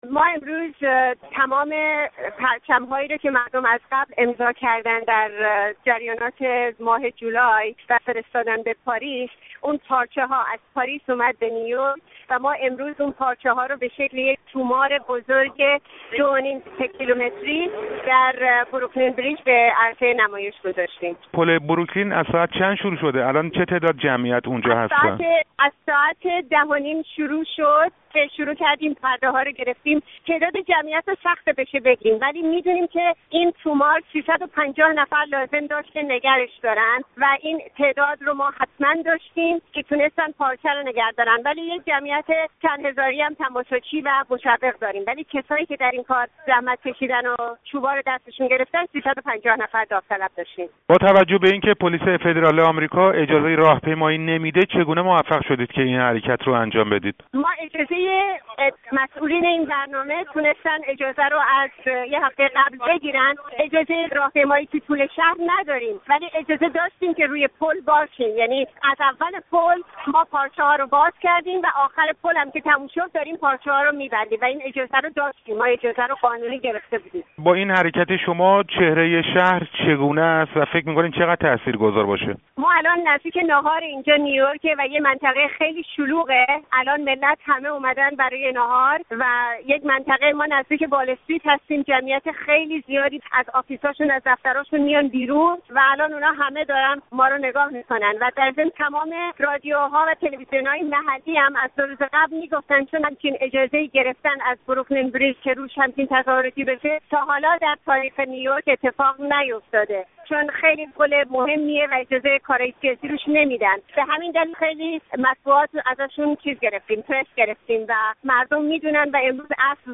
گفت‌وگو با يكى از سازماندهندگان برنامه اعتراضى نیویورک